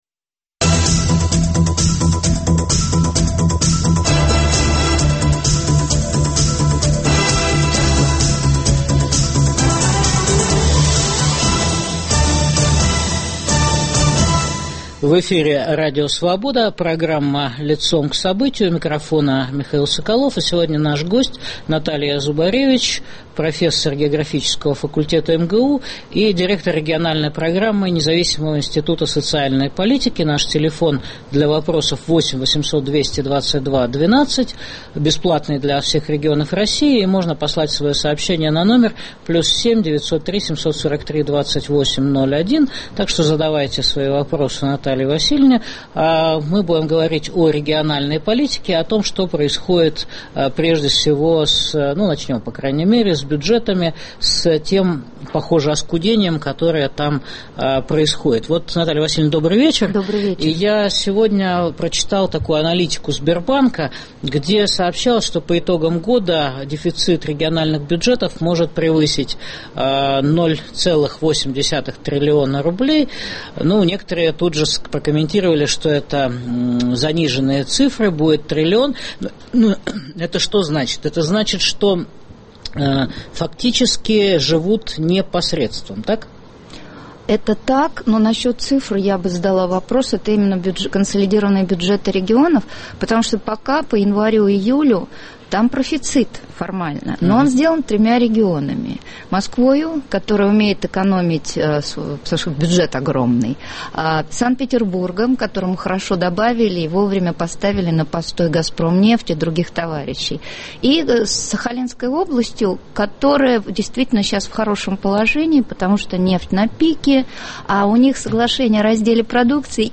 Может ли Россия прокормить Кремль? В студии программы - Наталья Зубаревич , директор региональной программы Независимого института социальной политики, профессор географического факультета МГУ. Беседуем о политике правительства Дмитрия Медведева и всё нарастающем бюджетном кризисе в регионах России.